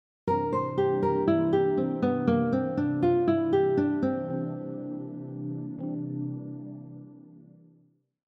Example 4 includes an arpeggio note skipping pattern with other scale notes, and chromatic notes.
Dominant 7 arpeggio example 4
Dominant-7-arpeggio-example-4.mp3